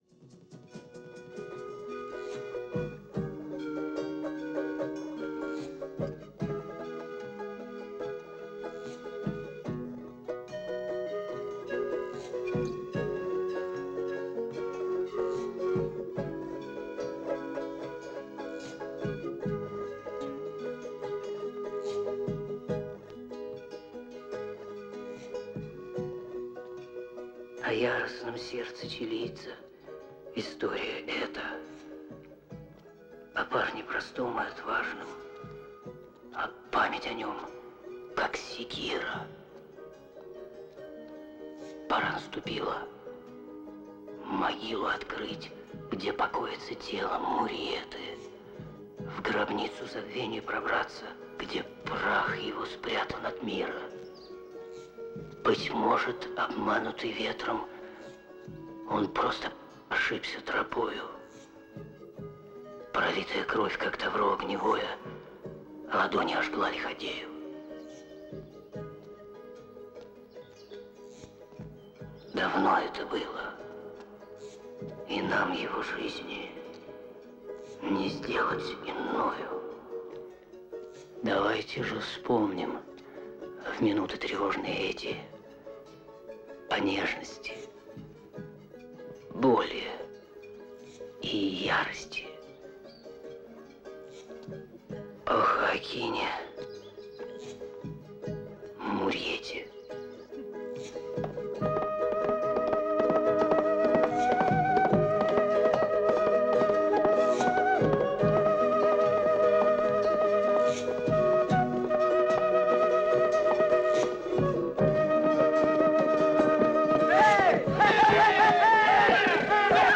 Музыка кино